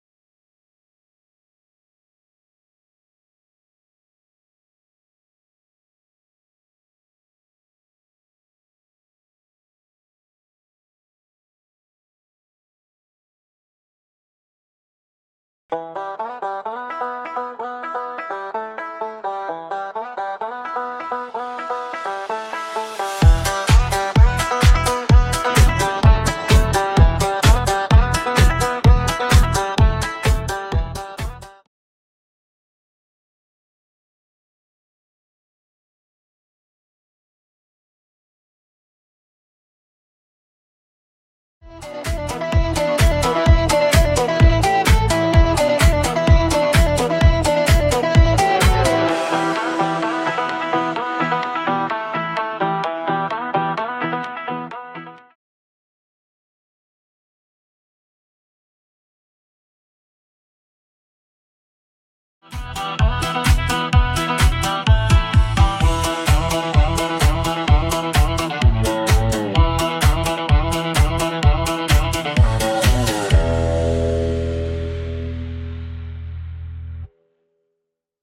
Theme Song: